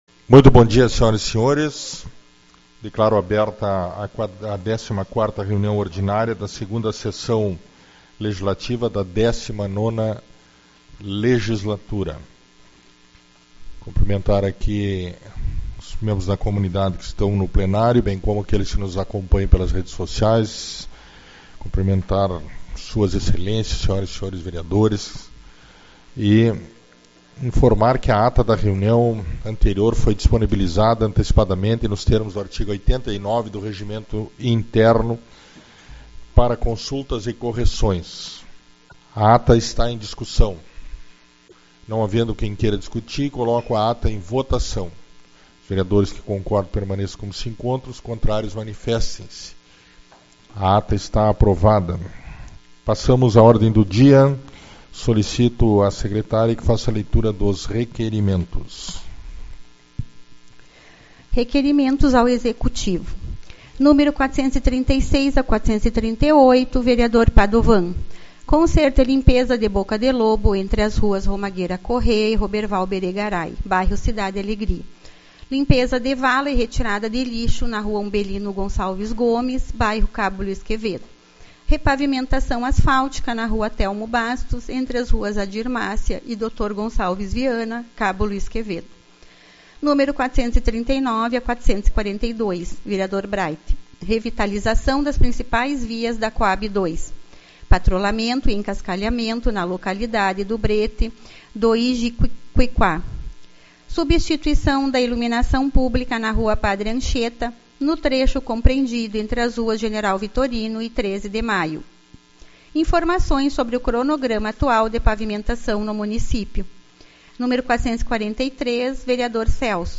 24/03 - Reunião Ordinária